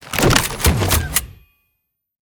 draw.ogg